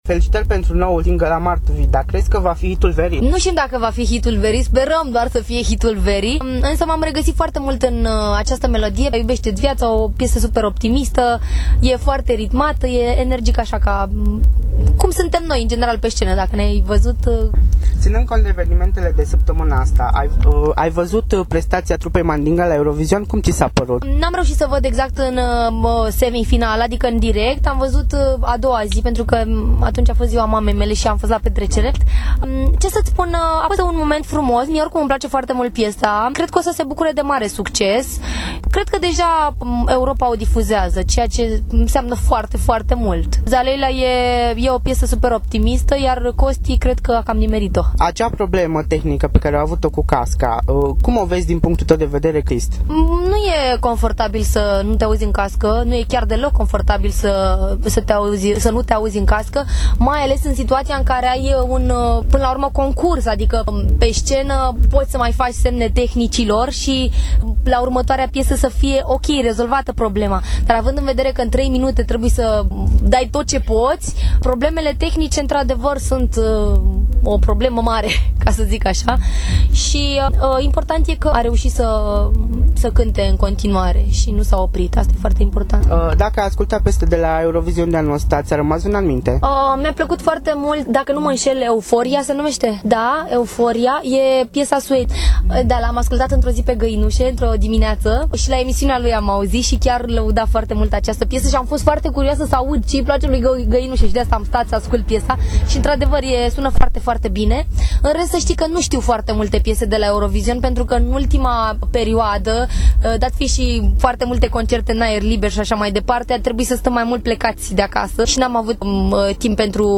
Cunoscuta interpretă, Elena Gheorghe, care a reprezentat România la concursul Eurovision din Moscova din 2009,  a fost prezentă la Cluj-Napoca, fiind invitată în cadrul deschiderii Zilelor Clujului.